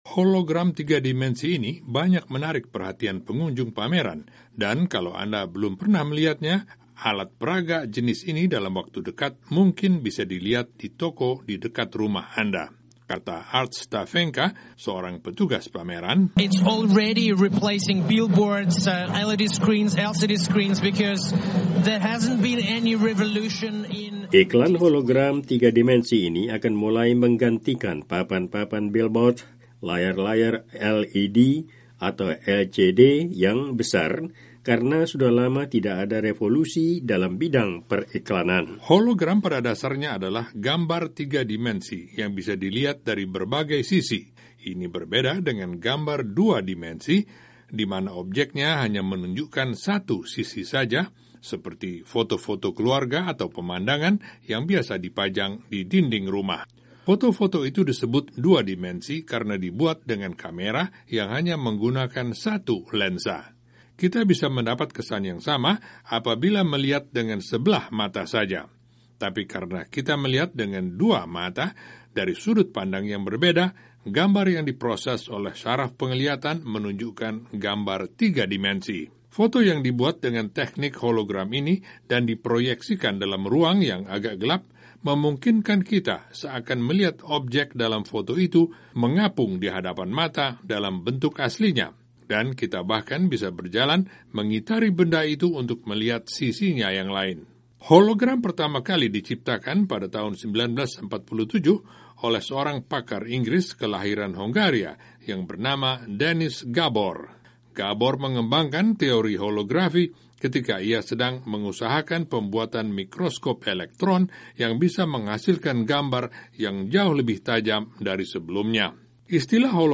mengirim laporan berikut dari pameran elektronik di Los Vegas